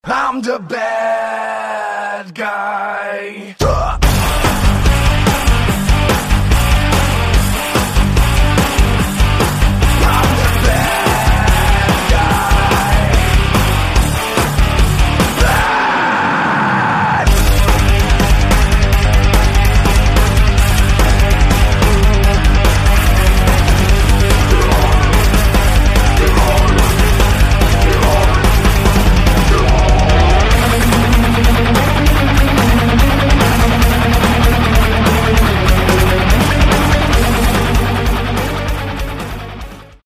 Громкие Рингтоны С Басами » # Кавер И Пародийные Рингтоны
Рок Металл Рингтоны